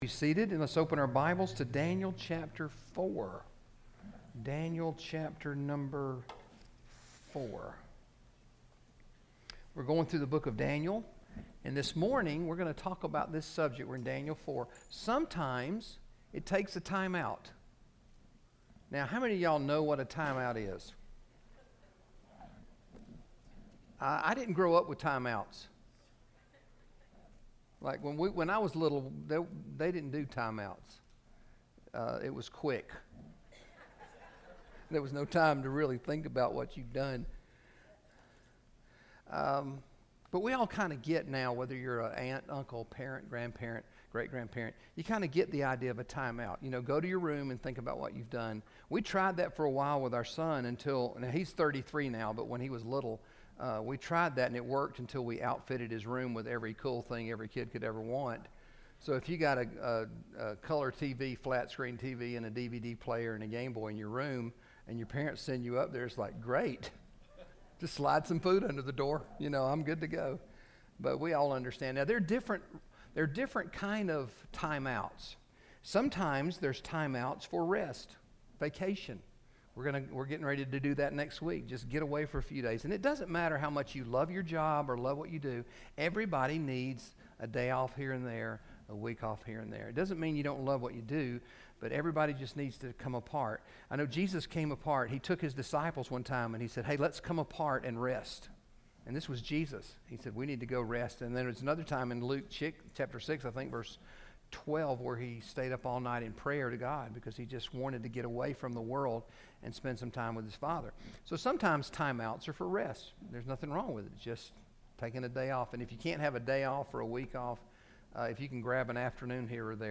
GCC Sermons | Gwinnett Community Church Sermons